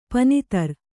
♪ pani tar